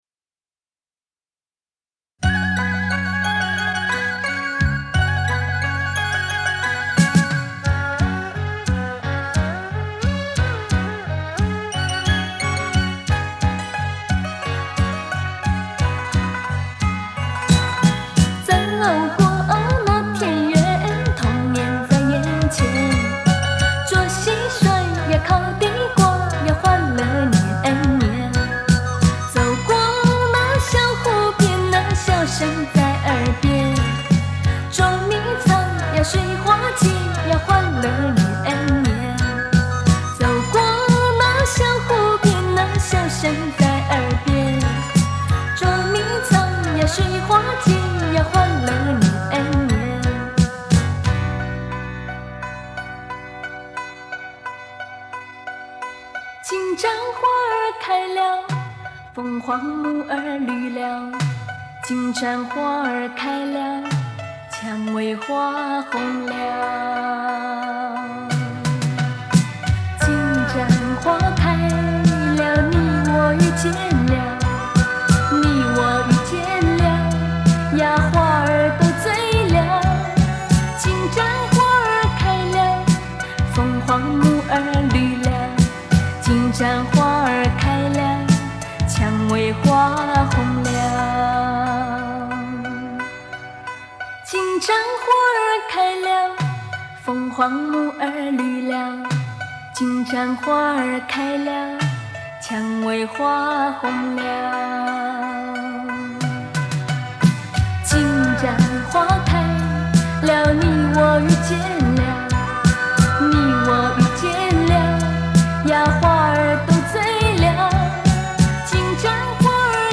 音源介质：磁带